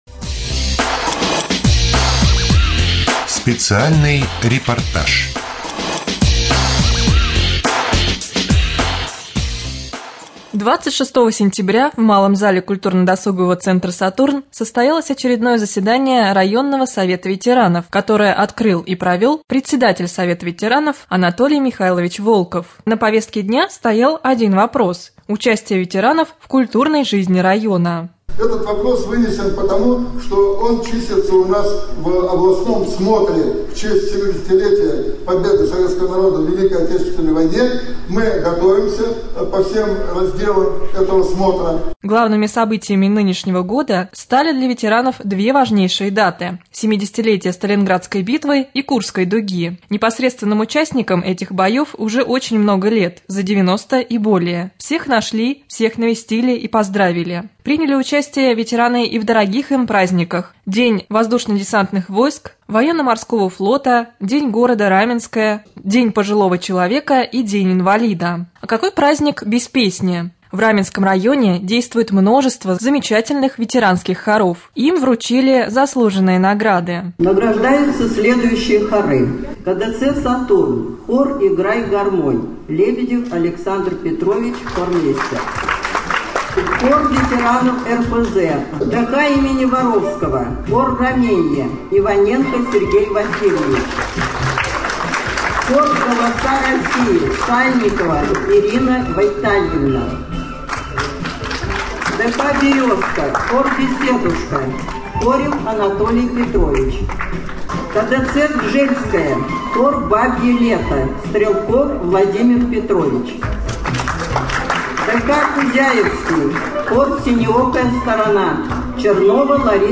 09.10.2013г в эфире раменского радио - РамМедиа - Раменский муниципальный округ - Раменское
2.Рубрика «Специальный репортаж». В малом зале КДЦ «Сатурн»  состоялось очередное заседание Районного совета ветеранов.